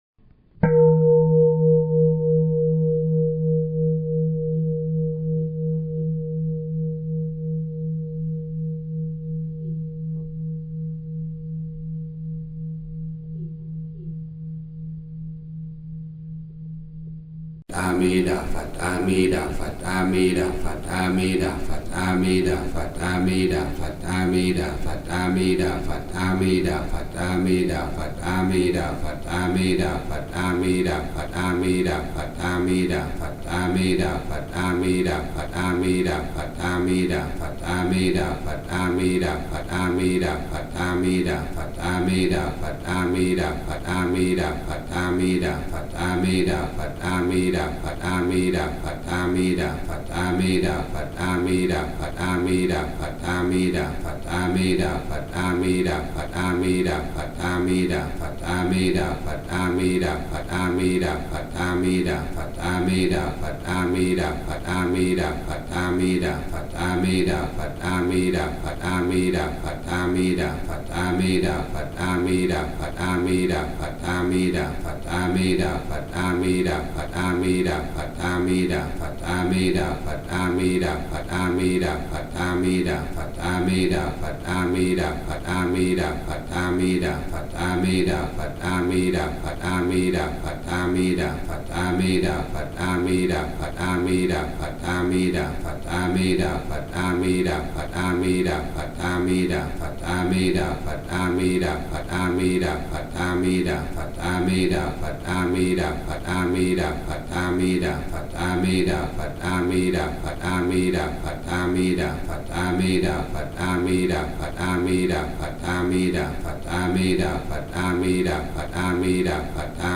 Thể loại: Nhạc Niệm Phật